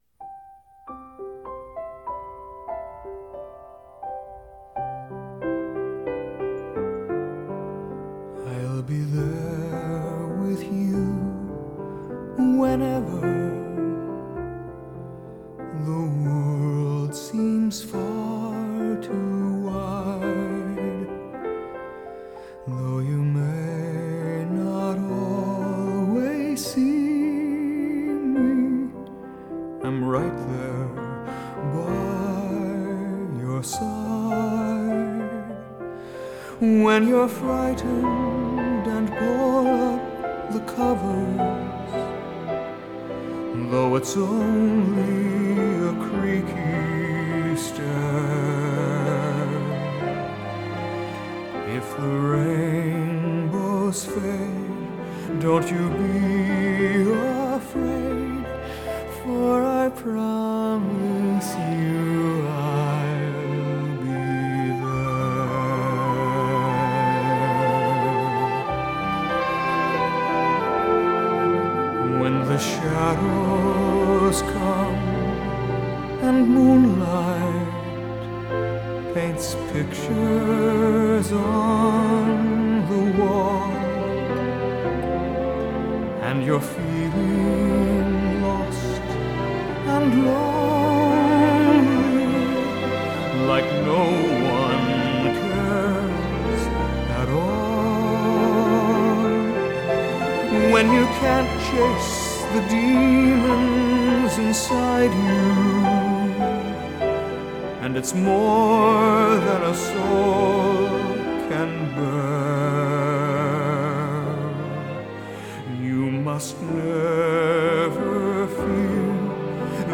Y ahora bajo un poco el pistón